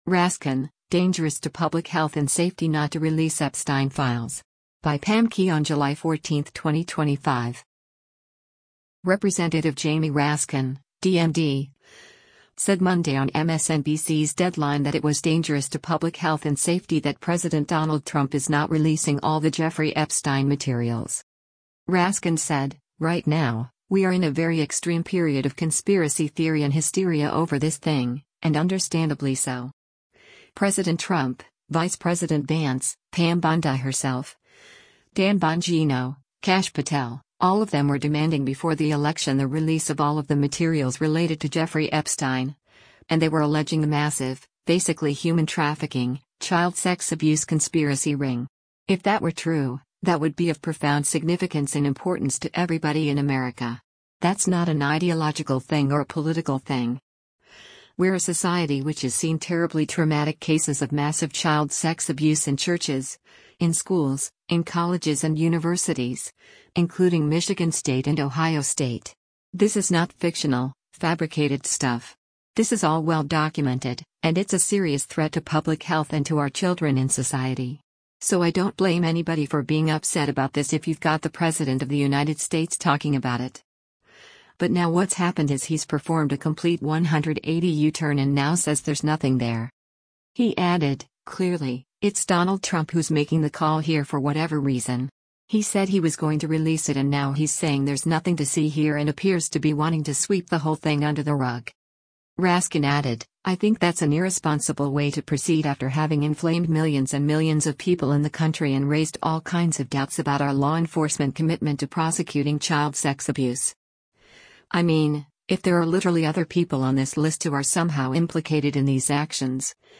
Representative Jamie Raskin (D-MD) said Monday on MSNBC’s “Deadline” that it was “dangerous to public health and safety” that President Donald Trump is not releasing all the Jeffrey Epstein materials.